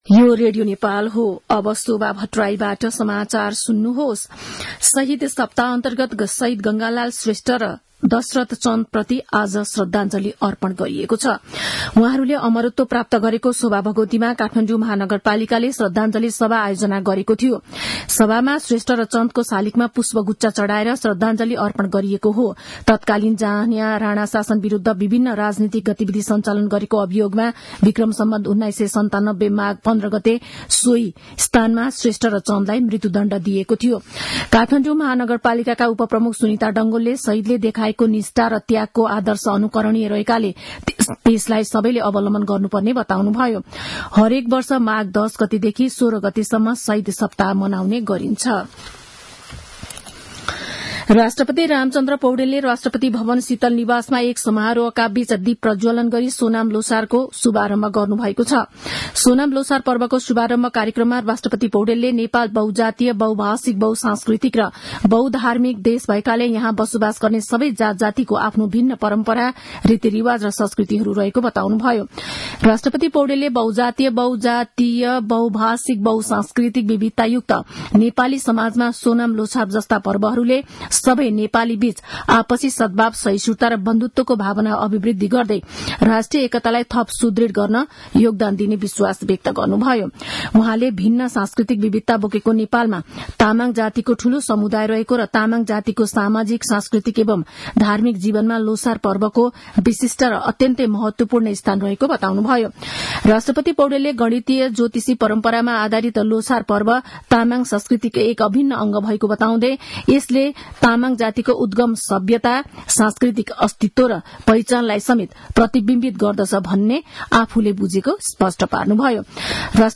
मध्यान्ह १२ बजेको नेपाली समाचार : १६ माघ , २०८१